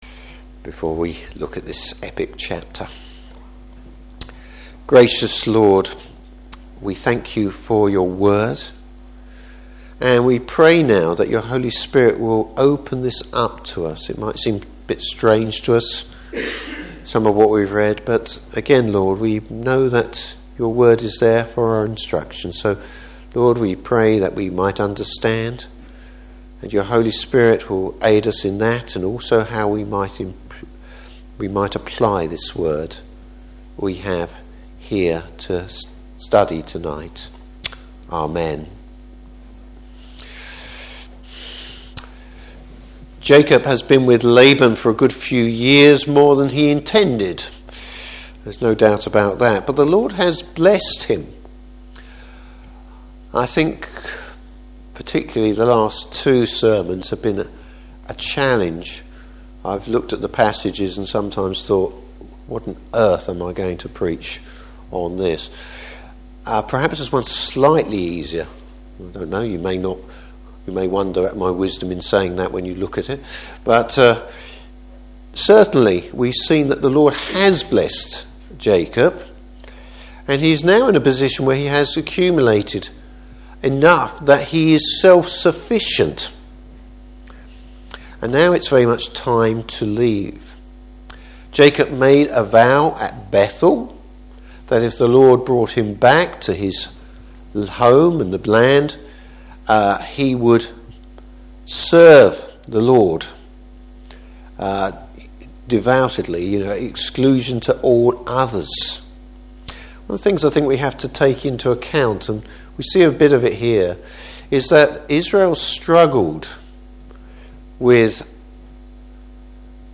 Service Type: Evening Service How God is starting to shape Jacob.